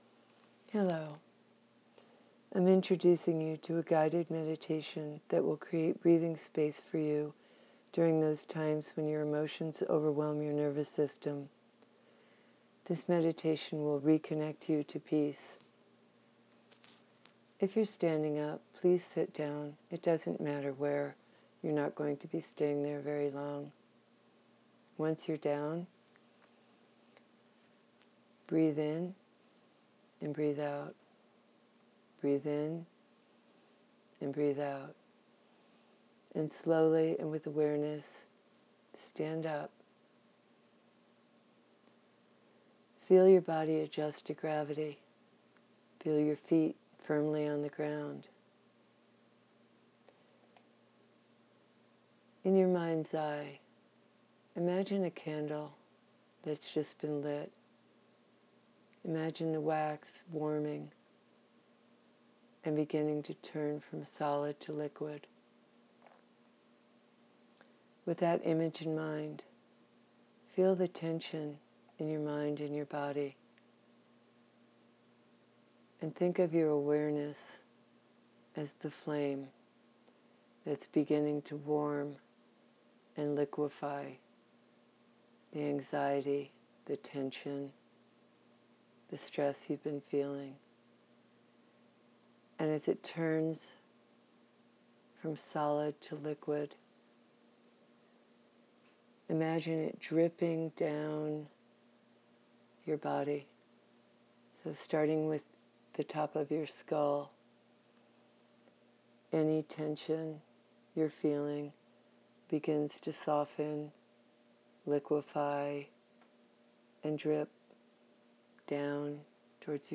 I am offering a very accessible one, the guided meditation below.
Standing Meditation